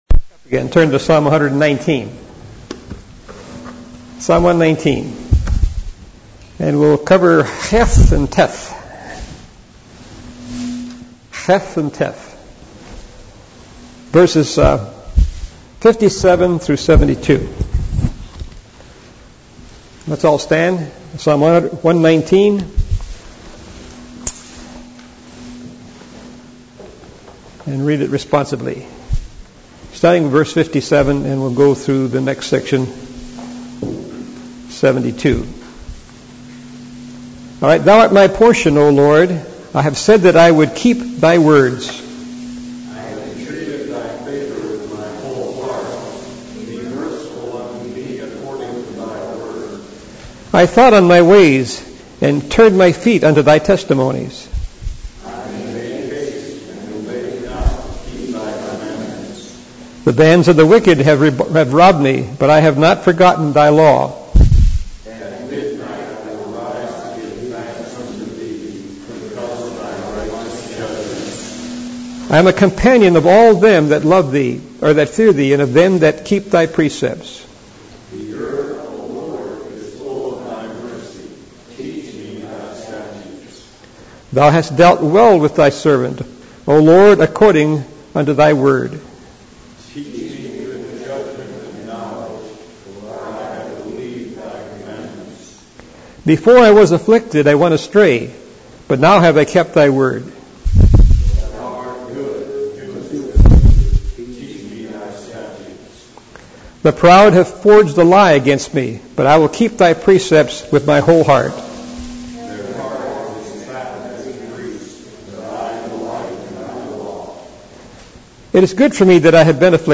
Series: 2011 July Conference Session: Morning Session